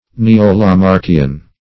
Ne`o-La*marck"i*an, a. & n.